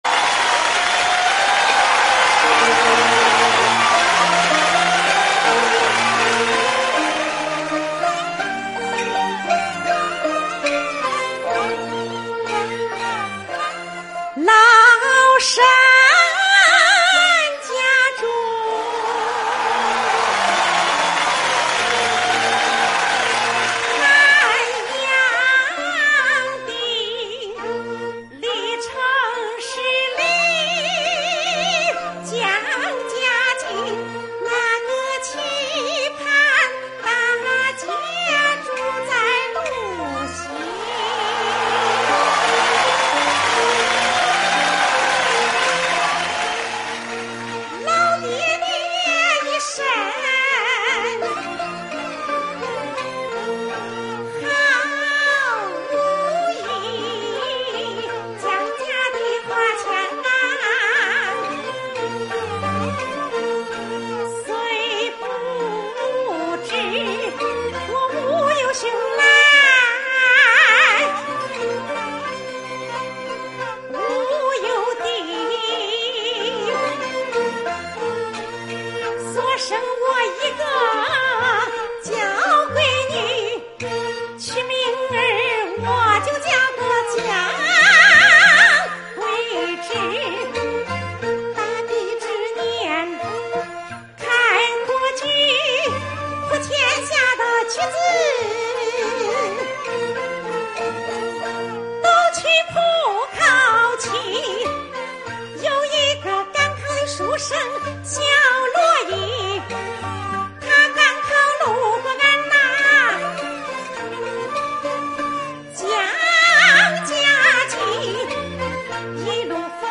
豫剧